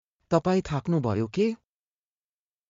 当記事で使用された音声（ネパール語および日本語）は全てGoogle翻訳　および　Microsoft TranslatorNative Speech Generation、©音読さんから引用しております。